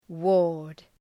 {wɔ:rd}